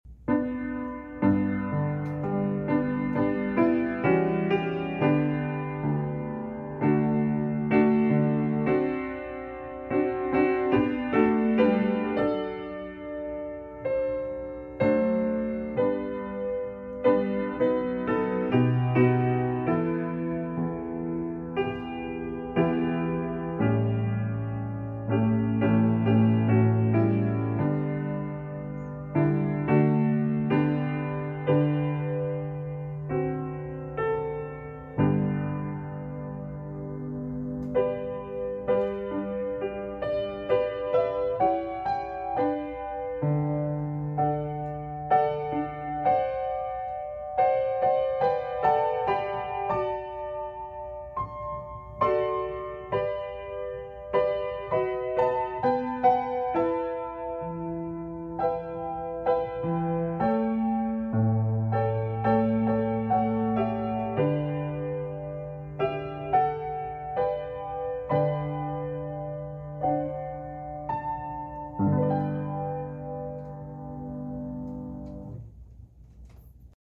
piano hymn